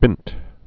(bĭnt)